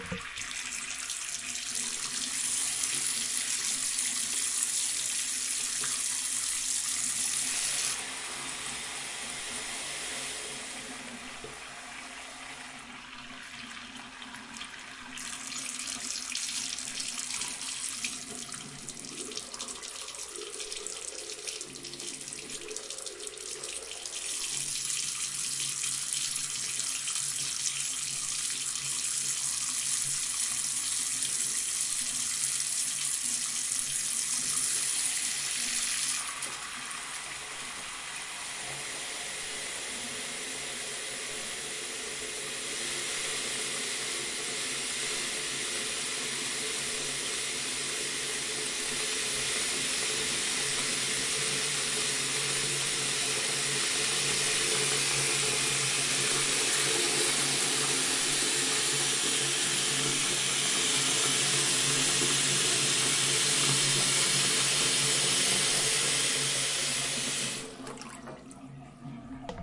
浴室的声音 " 水槽运行2
描述：吱吱作响的水龙头h4n＆amp;骑麦克风
Tag: 水槽 浴缸 浴室 排水 水龙头